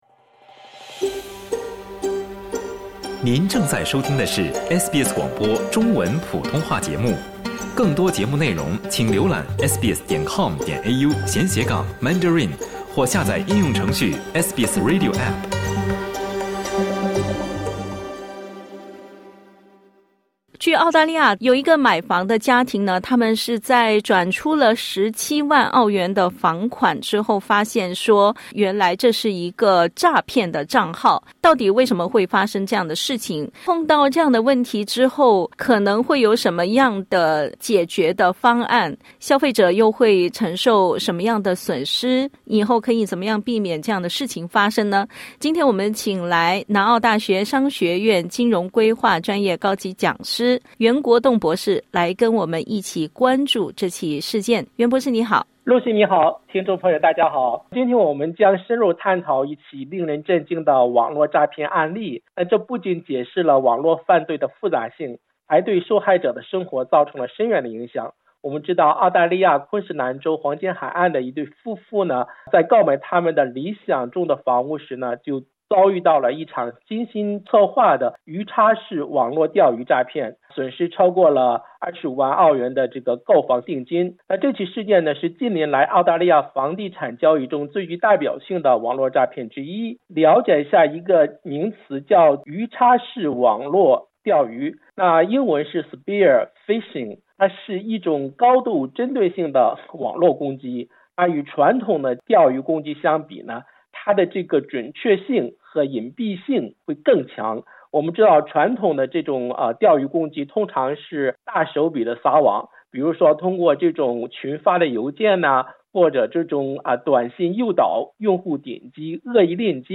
（点击音频收听详细采访） 最近在澳洲又发生了一起购房者的巨额购房首付款被精准骗走的案例。